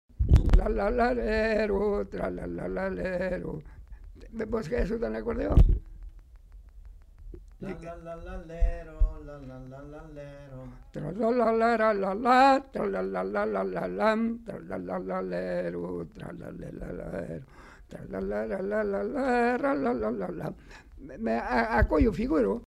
Aire culturelle : Savès
Genre : chant
Effectif : 1
Type de voix : voix d'homme
Production du son : fredonné
Danse : quadrille